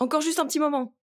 VO_ALL_Interjection_04.ogg